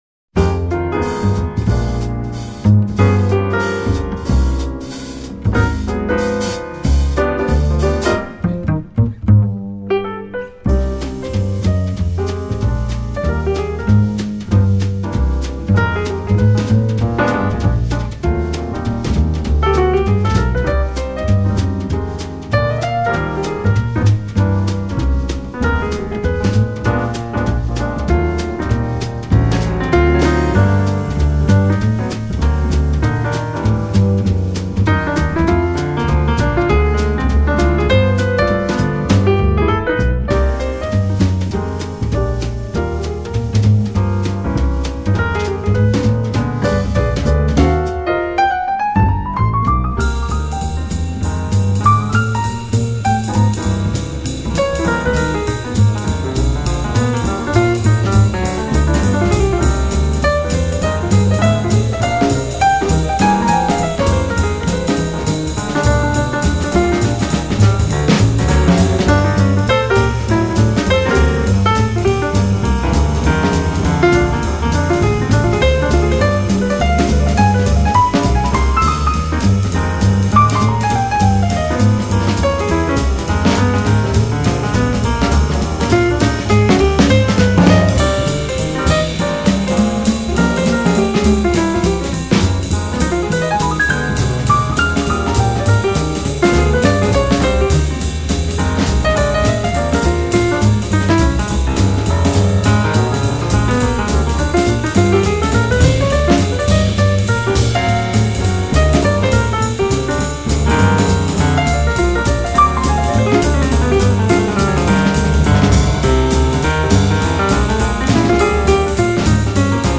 的睿智与优雅，但是他的钢琴很流畅，很细腻， 是温柔而沉静的，你不需要
爵士鋼琴專輯